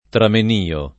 tramenio [ tramen & o ] s. m.